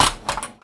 R - Foley 104.wav